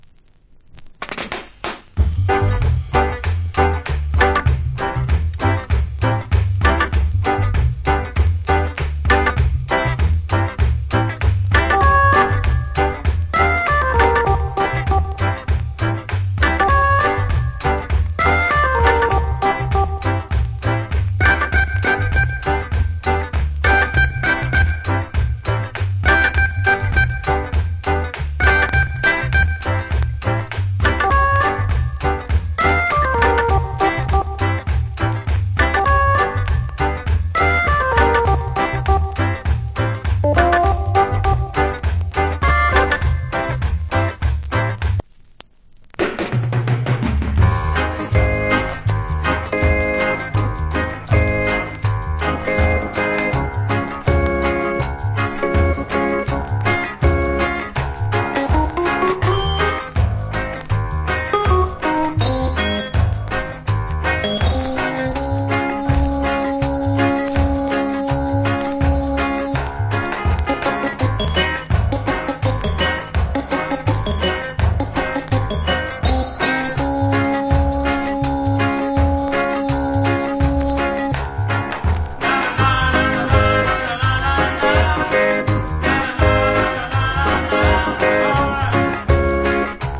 Wicked Early Reggae Inst.